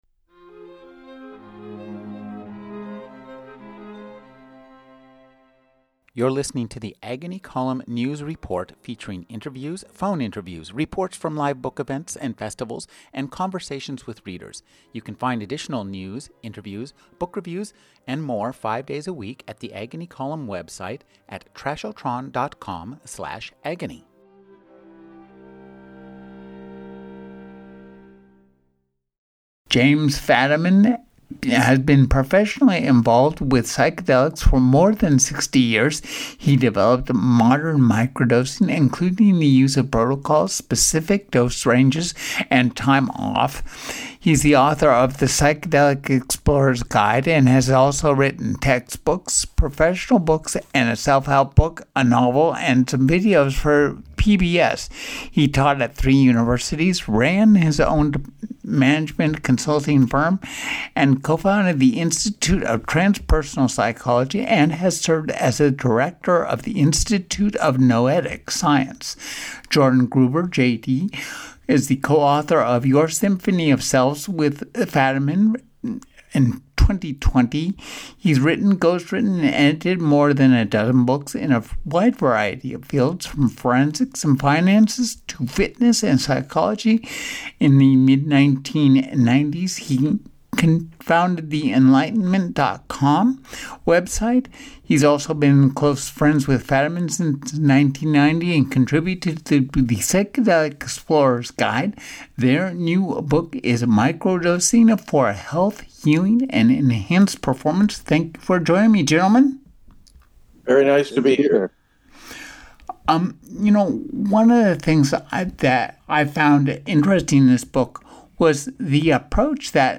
Indepth Interviews